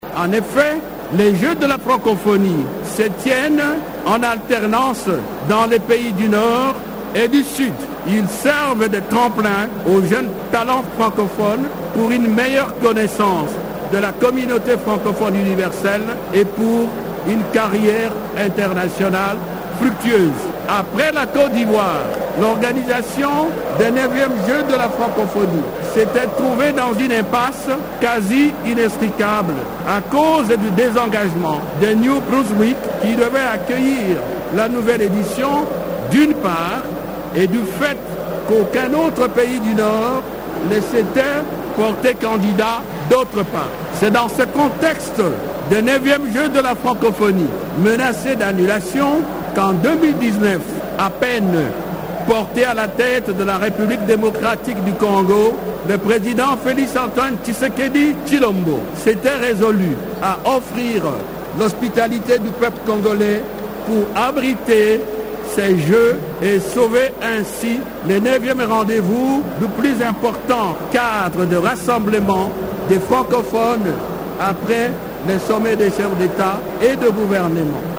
Le ministre des Affaires étrangères, Christophe Lutundula, a rappelé ce vendredi 28 juillet à Kinshasa qu’en dépit de la conjoncture financière et économique très difficile, malgré l’agression, le gouvernement de la RDC a relevé les défis de l’organisation des IXes Jeux de la Francophonie. Il l’a dit dans son discours de bienvenue, prononcé à l’ouverture des IXes Jeux de la Francophonie.